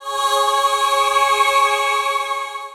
Key-choir-183.1.1.wav